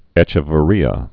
(ĕchə-və-rēə)